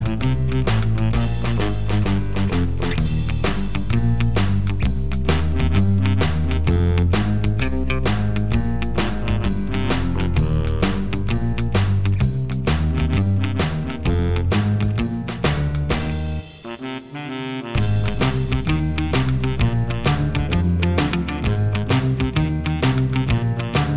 Minus Guitars Pop (1960s) 2:07 Buy £1.50